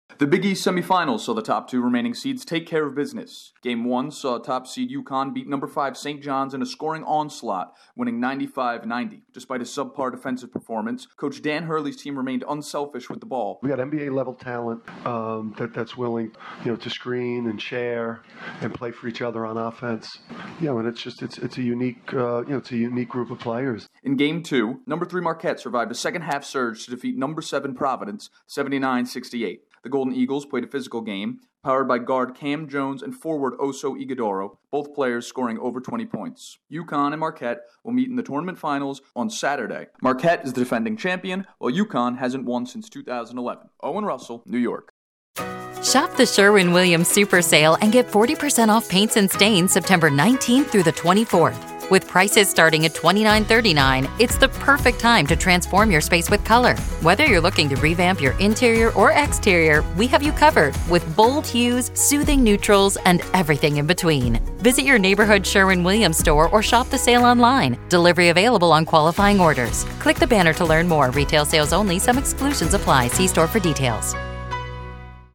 There are no surprises in the Big East semifinals. Correspondent